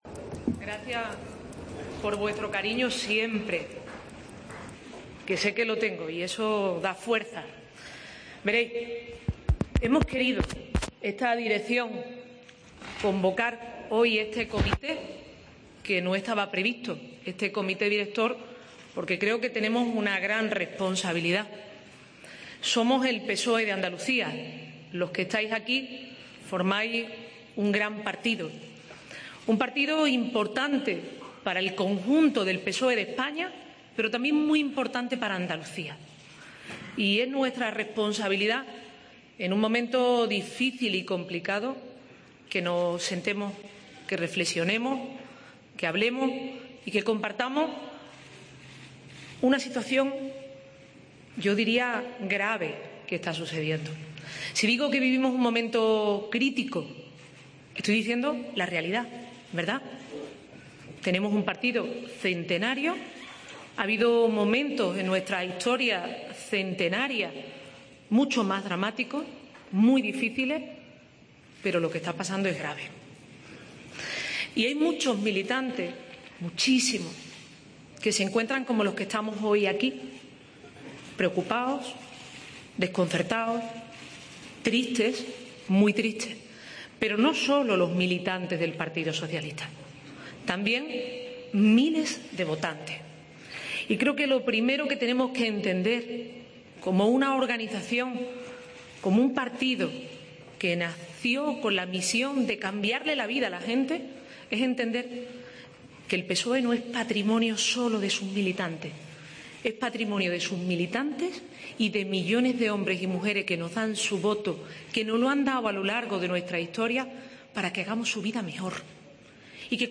Escucha a Susana Díaz, presidenta socialista de Andalucía, tras reunirse con su ejecutiva regional y comité director